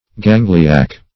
Gangliac \Gan"gli*ac\